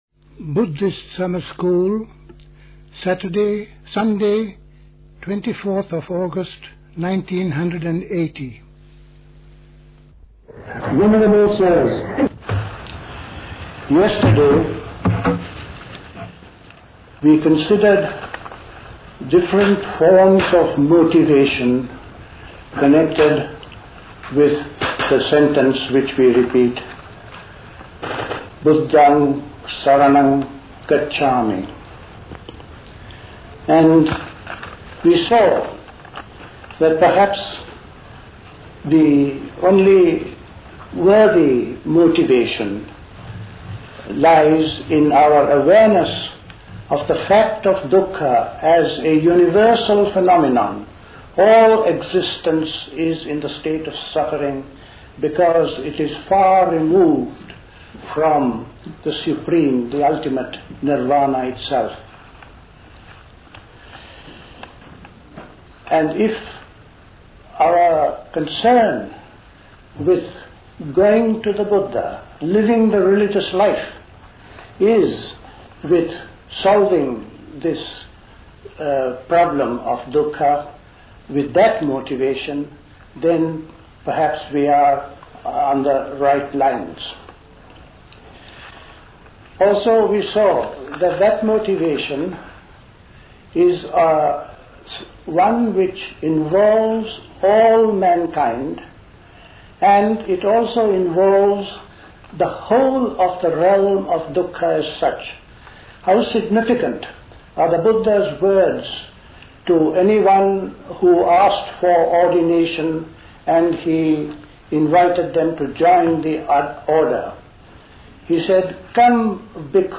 A talk
at High Leigh Conference Centre, Hoddesdon, Hertfordshire